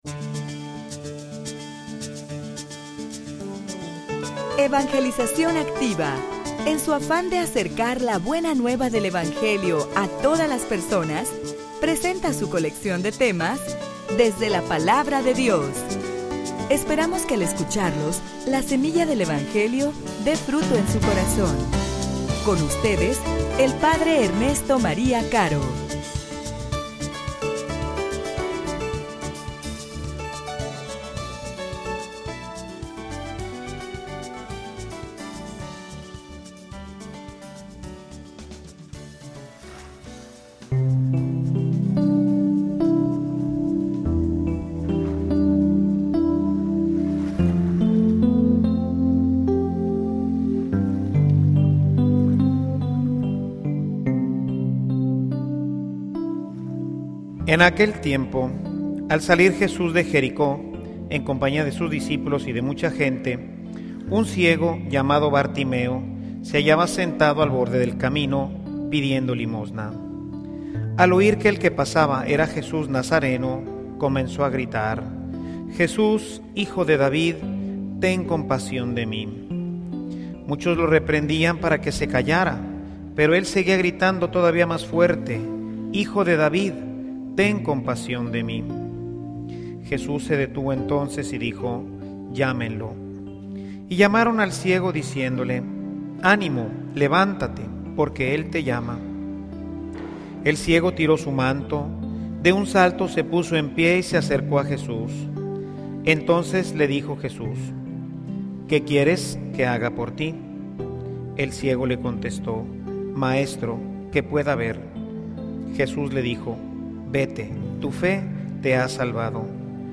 homilia_Un_encuentro_que_libera.mp3